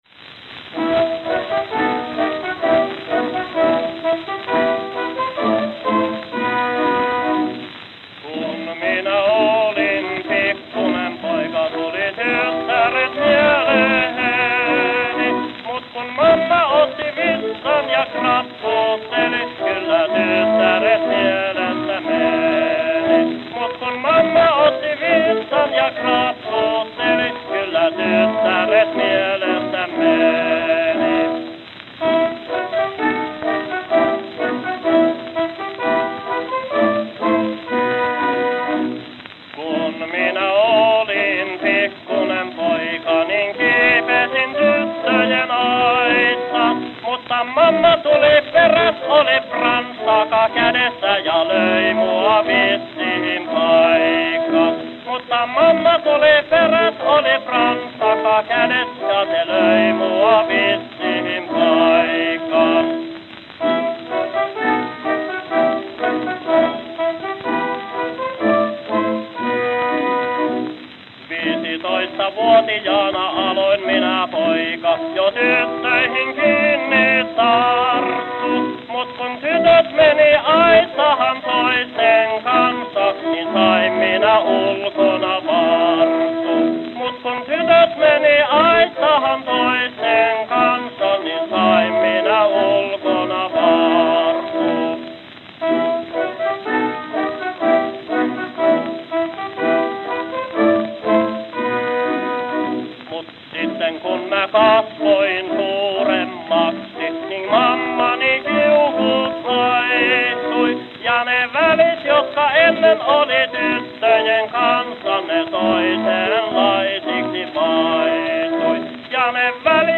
New York, New York New York, New York
Note: Worn.